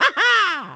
mario-64-haha.mp3